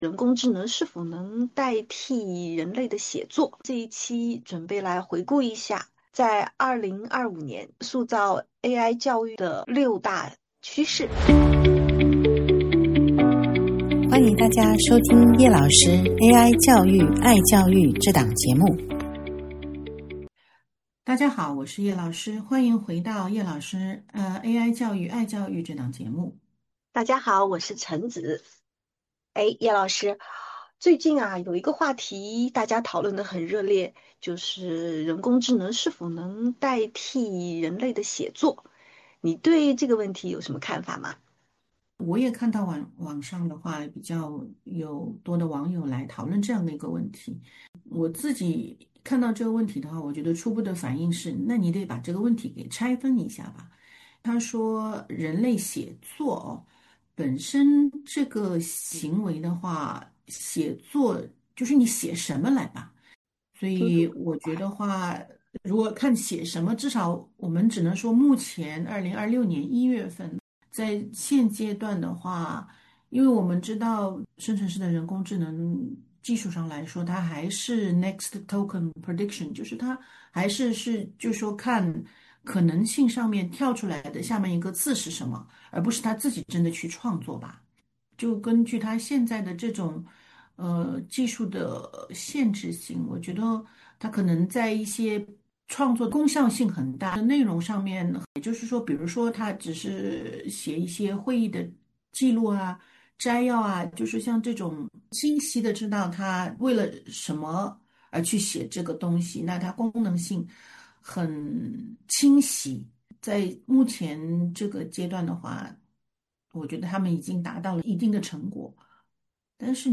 本期节目，两位主持人在简短交换了一下对热点话题--“人工智能是否能代替人类写作”的想法以后，开始主题：总结2025年AI教育的六大趋势。